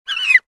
Звук пищащего индюка